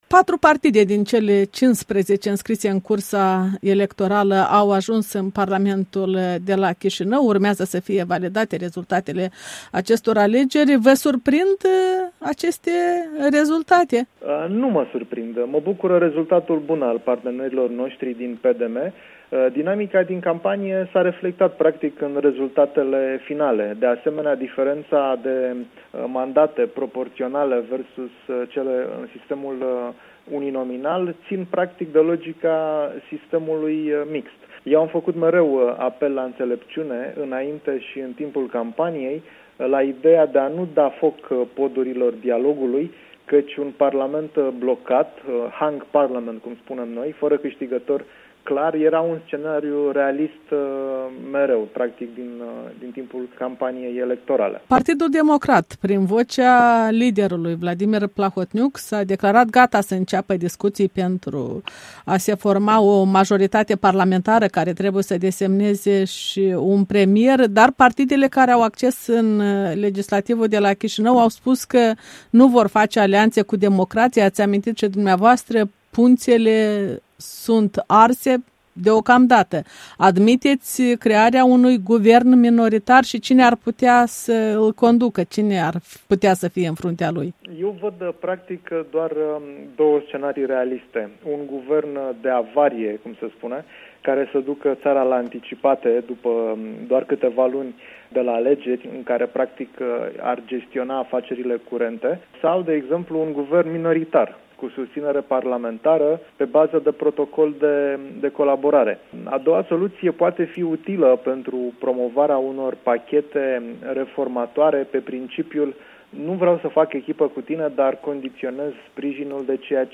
Interviu cu eurodeputatul PSD, președintele Delegației Parlamentului European pentru Republica Moldova.
Interviu cu eurodeputatul român Andi Cristea